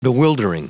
Prononciation du mot bewildering en anglais (fichier audio)
Prononciation du mot : bewildering